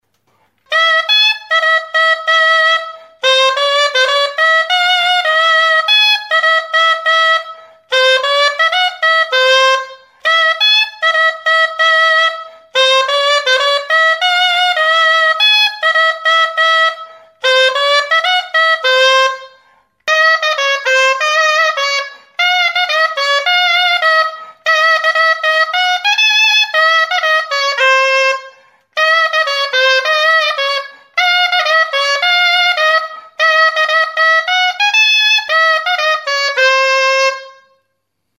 Aerófonos -> Lengüetas -> Doble (oboe)
Grabado con este instrumento.
EUROPA -> EUSKAL HERRIA